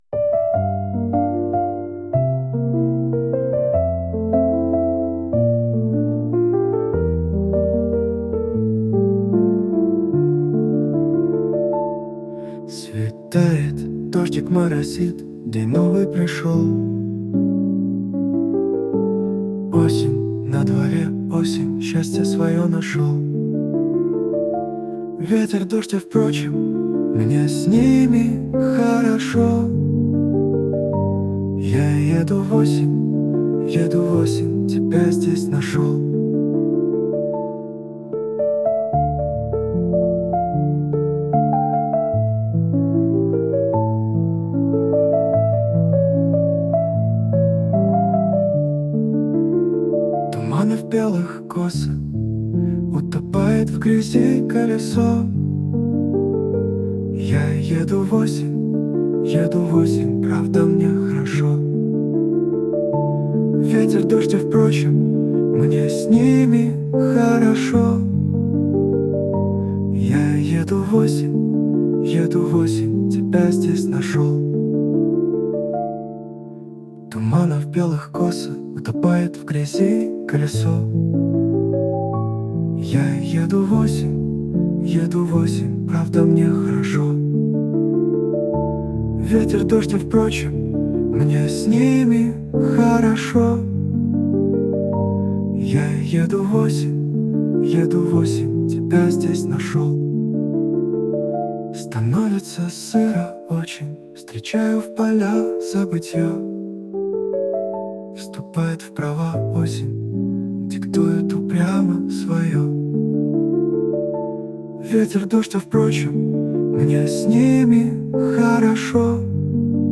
Музика підготовлена з ШІ.
ТИП: Пісня
СТИЛЬОВІ ЖАНРИ: Ліричний